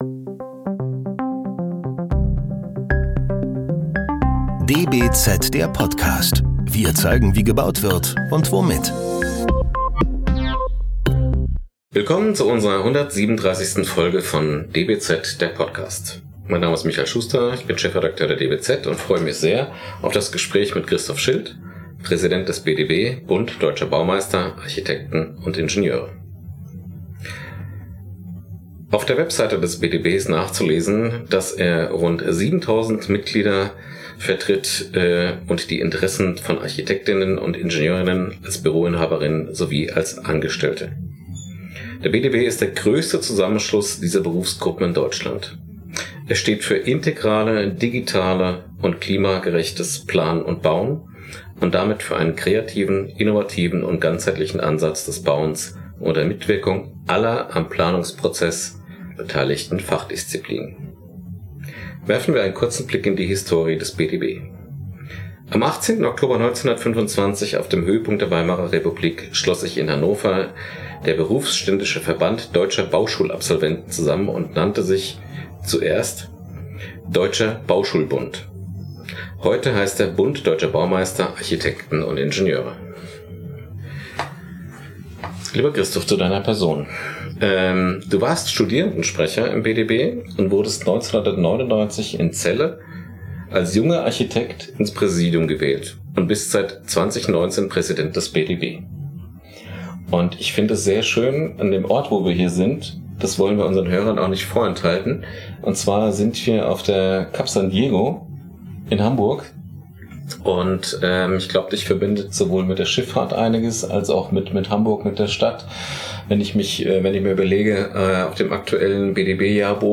Das DBZ Team bespricht im Podcast relevante Fragen der Architektur, der Bautechnik und der Baupraxis und lädt Mitdenker, Vordenker und Querdenker der Branche ein, mitzudiskutieren.